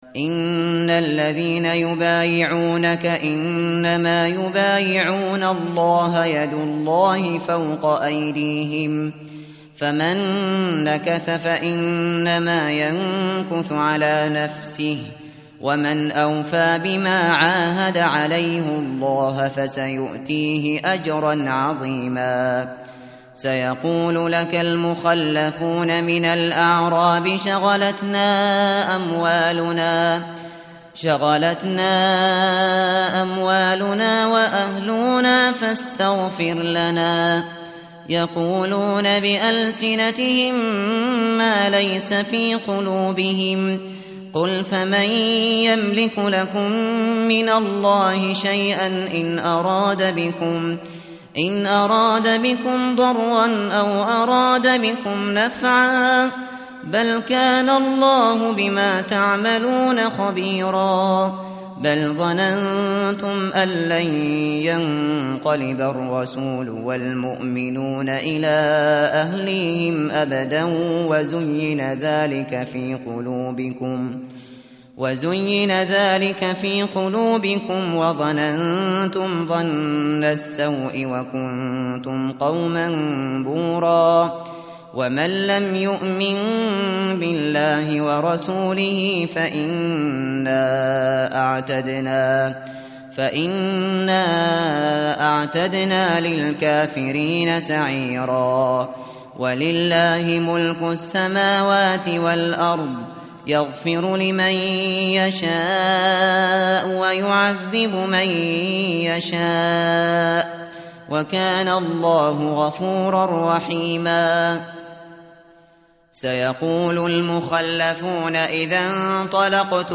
متن قرآن همراه باتلاوت قرآن و ترجمه
tartil_shateri_page_512.mp3